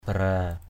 /ba-ra:/